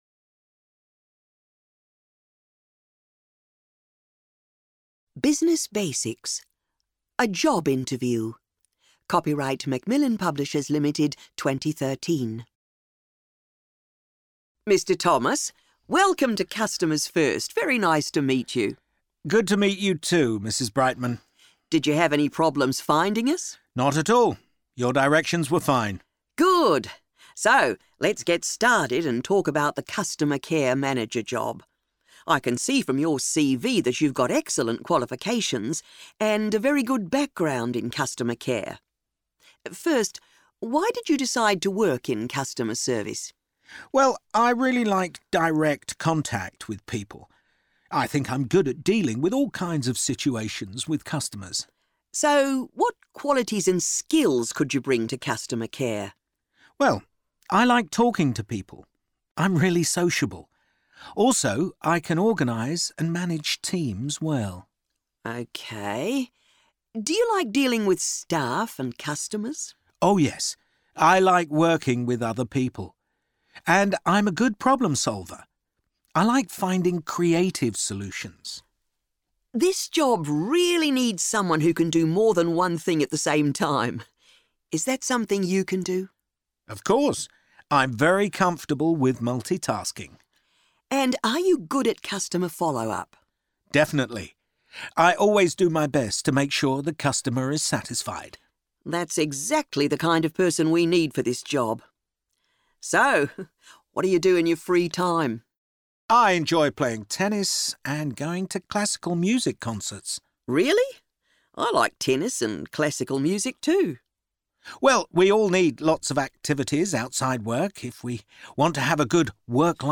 Business Basics: A job interview